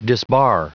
Prononciation du mot disbar en anglais (fichier audio)
Prononciation du mot : disbar